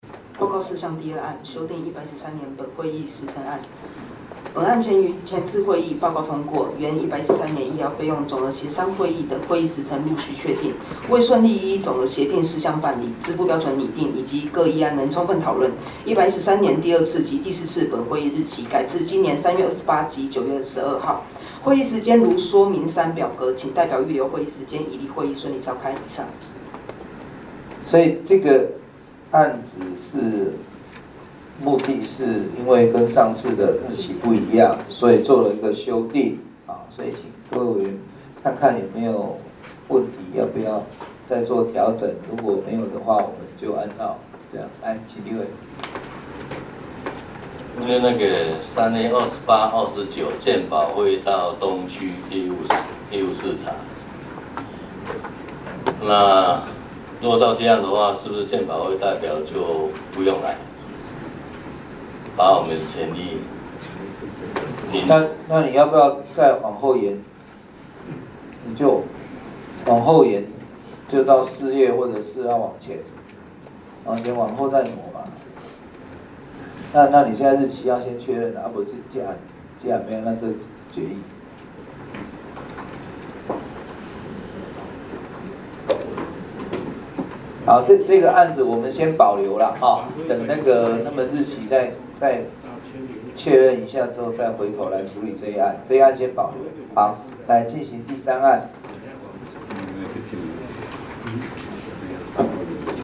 全民健康保險醫療服務給付項目及支付標準共同擬訂會議113年第1次會議紀錄實錄(113.1.30新增)